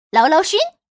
Index of /xiaoxiang/update/3018/res/sfx/changsha_woman/